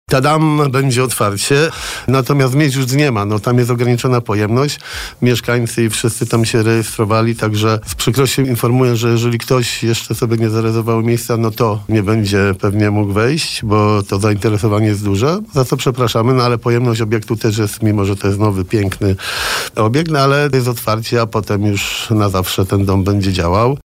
– Tadam, tak, będzie otwarcie! Natomiast miejsc już nie ma. Niestety placówka ma ograniczoną pojemność, mimo, ze to piękny, nowy obiekt. Z przykrością więc informuję, że jeżeli ktoś jeszcze sobie nie zarezerwował miejsca, to nie pewnie mógł wejść, za co przepraszamy – mówił na naszej antenie prezydent Jarosław Klimaszewski.